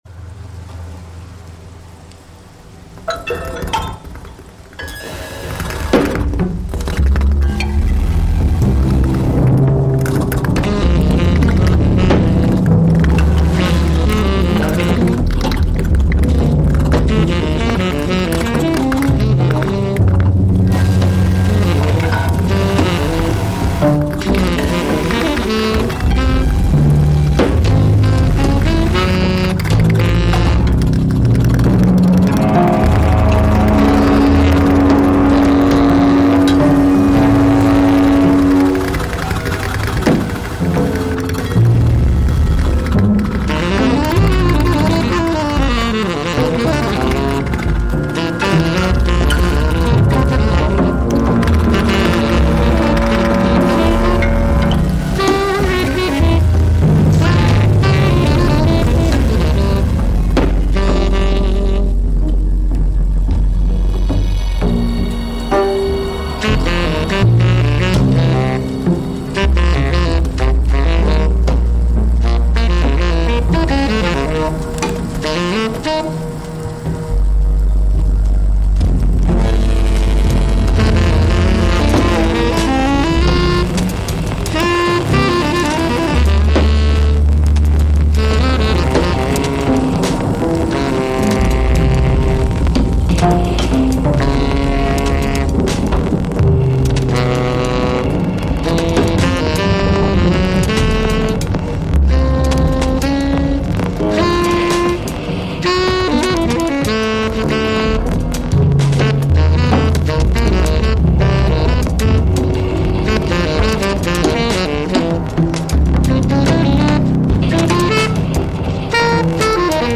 live streamed on 26 May 2023